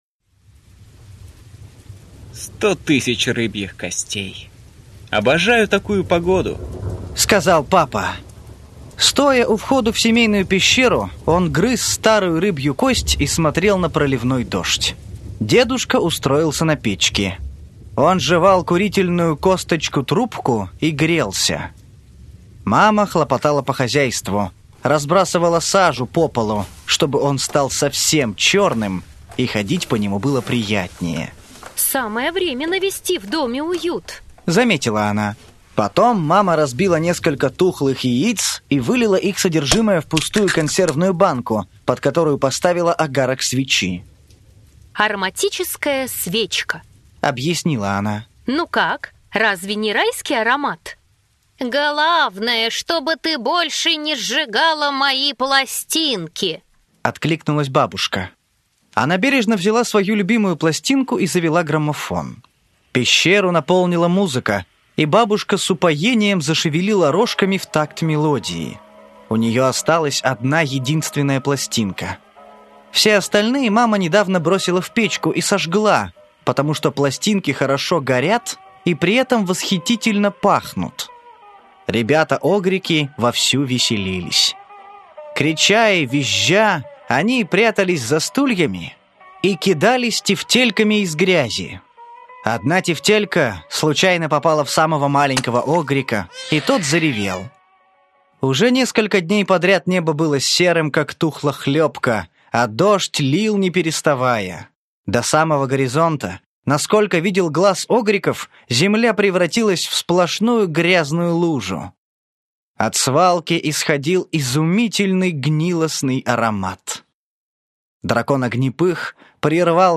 Аудиокнига Огрики и большой переезд. Сборник историй | Библиотека аудиокниг